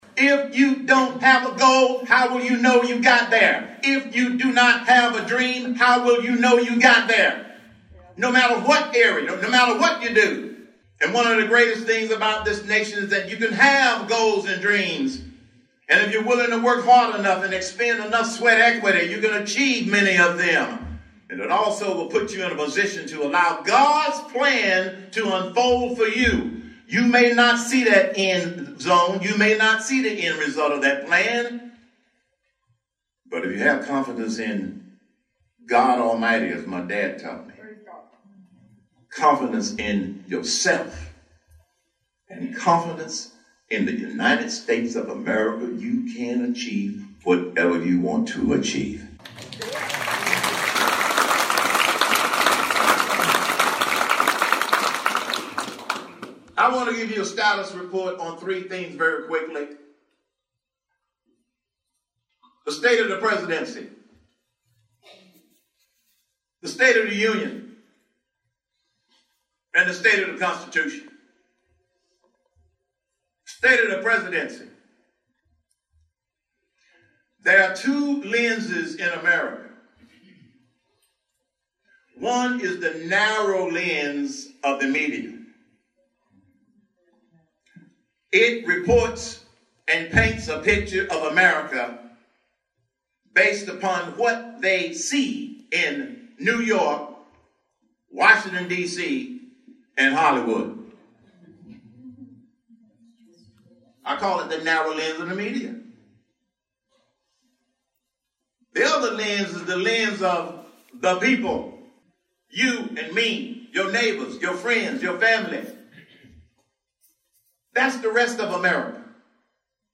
FRESNO (KMJ) — National business leader and former Republican party presidential candidate shared his views on the GOP during a speech in Fresno.
Former 2012 presidential candidate Herman Cain headlined the annual Fresno County Republican Party Lincoln Reagan dinner Thursday night.